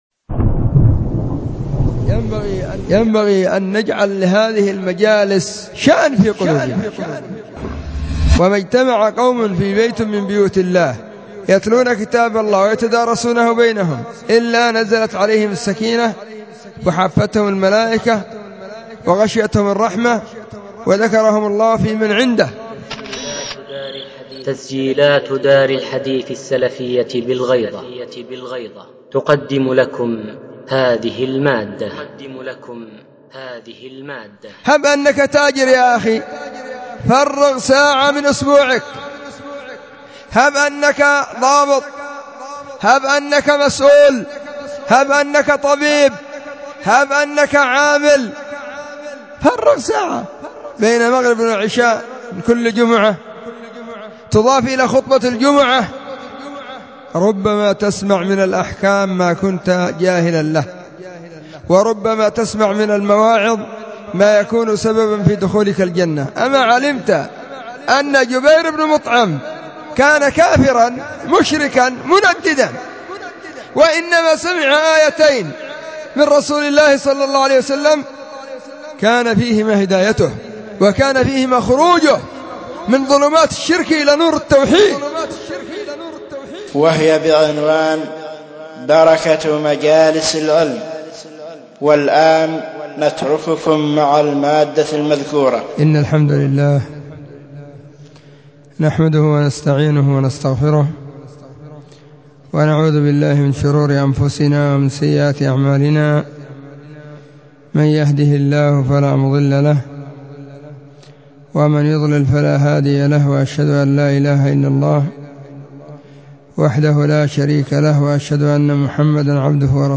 محاضرة بعنوان (( بركة مجالس العلم ))
📢 مسجد الصحابة – بالغيضة – المهرة، اليمن حرسها الله،
محاضرة_بعنوان_بركة_مجالس_العلم_.mp3